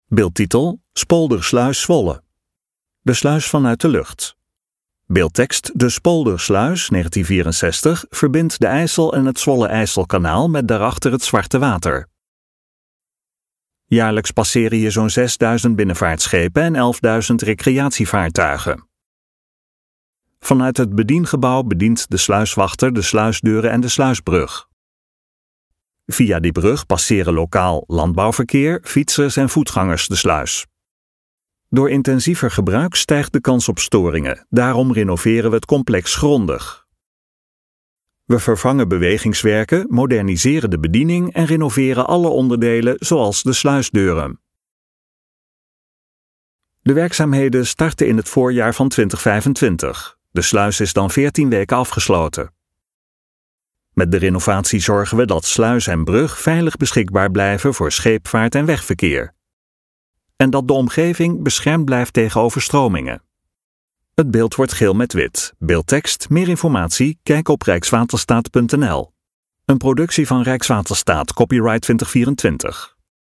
RUSTIGE MUZIEK TOT HET EIND VAN DE VIDEO (Jaarlijks passeren hier zo'n 6.000 binnenvaartschepen en 11.000 recreatievaartuigen.
DE RUSTIGE MUZIEK EBT WEG (Het Nederlandse wapenschild met daarnaast: Rijkswaterstaat.